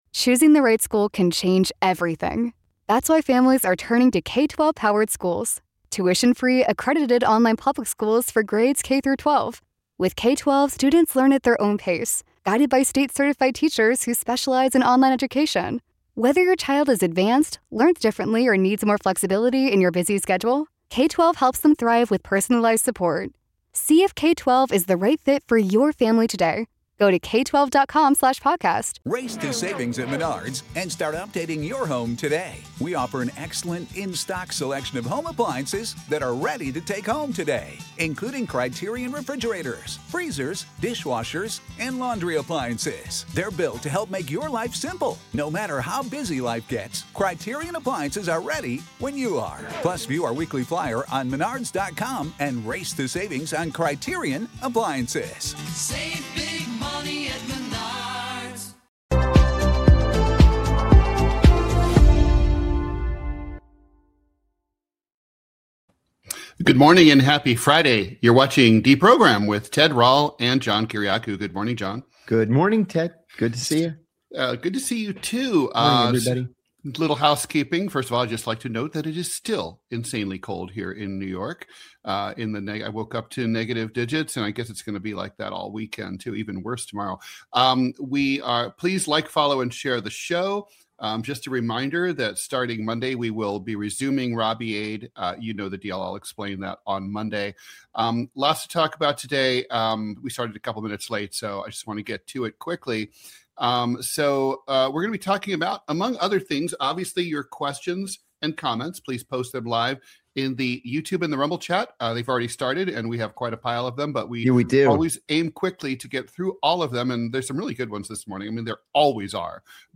Political cartoonist Ted Rall and CIA whistleblower John Kiriakou deprogram you from mainstream media every weekday at 9 AM EST.